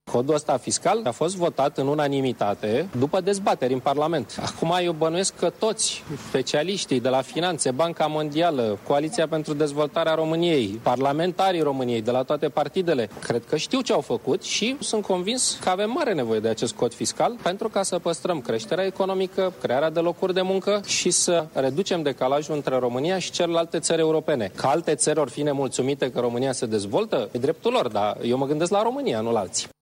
Ca o primă reacţie la afirmaţiile preşedintelui Klaus Iohannis, premierul Victor Ponta spune că România are nevoie de noul cod fiscal.